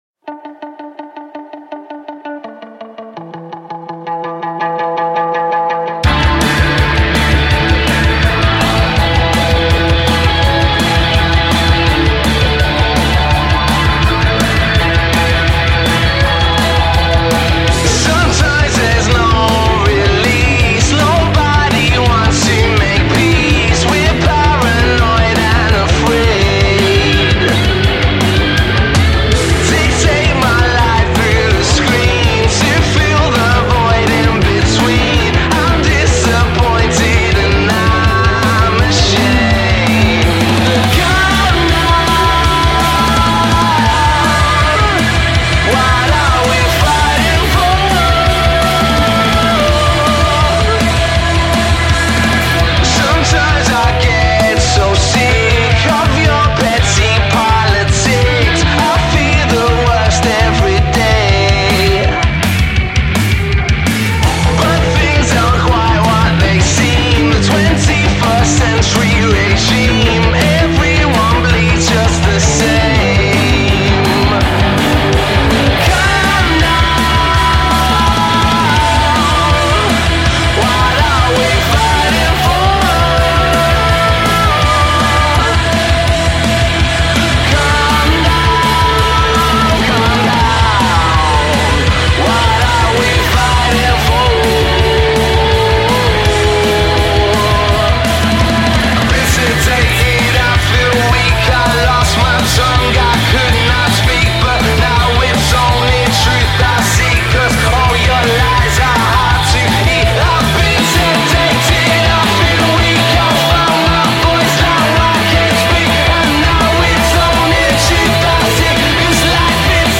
Birmingham quartet
the indie rockers